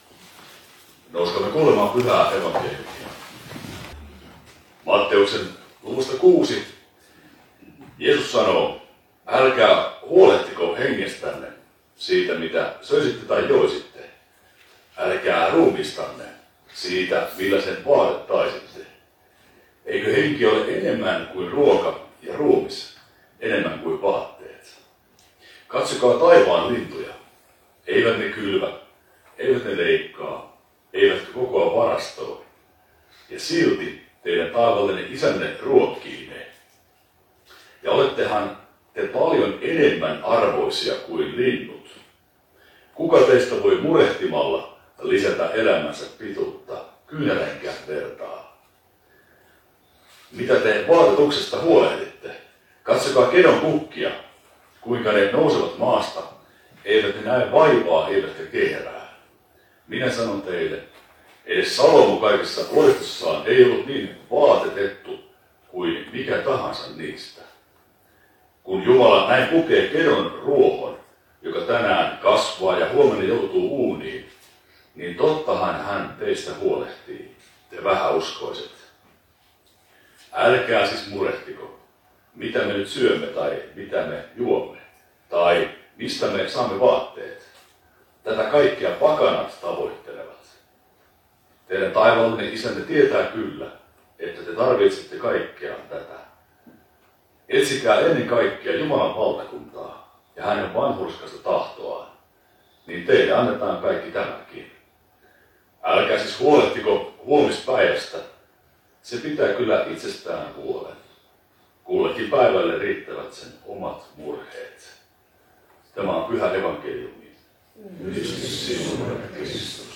saarna Kannuksessa SLEYn syysjuhlan messussa 16. sunnuntaina helluntaista